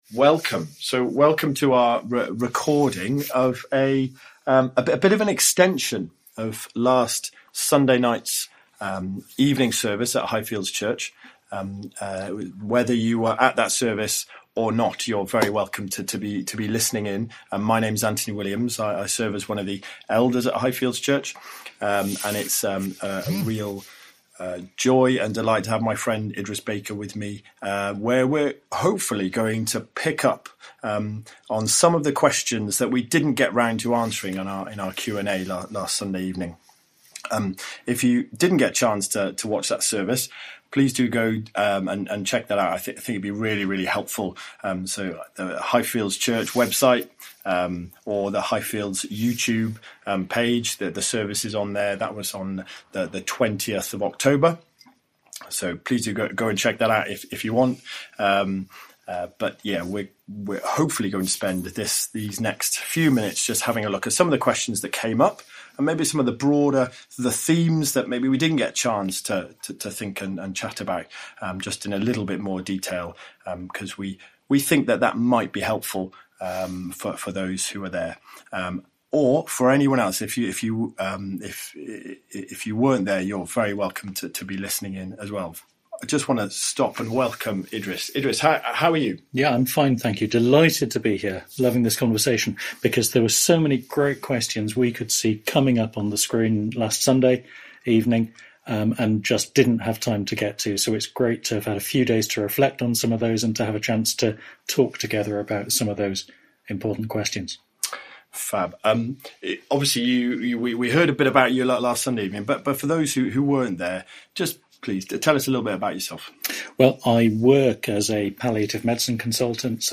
13 November 2024, Extended Q&A On the 20th October we had a Hot Topics evening at Highfields Church focussing on issues surrounding care at the end of life.